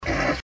Snort 3